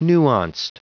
Prononciation du mot nuanced en anglais (fichier audio)
Prononciation du mot : nuanced
nuanced.wav